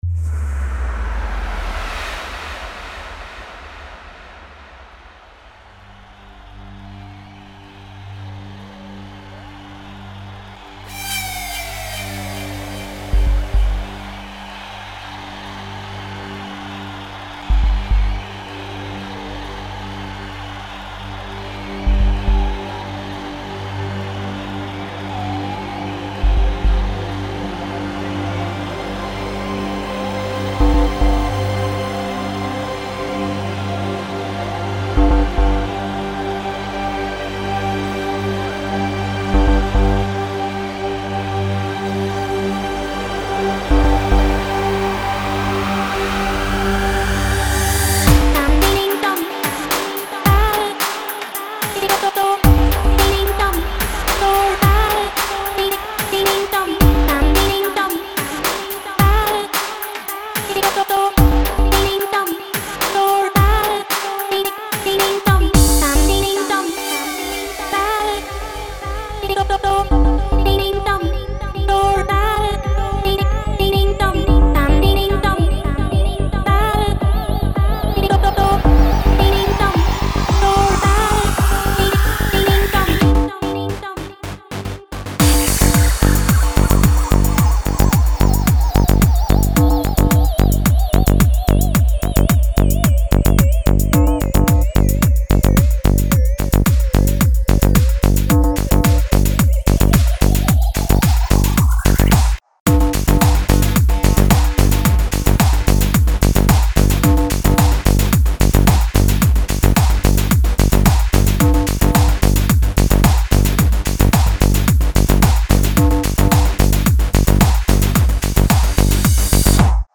Файл в обменнике2 Myзыкa->Psy-trance, Full-on
Style: Full On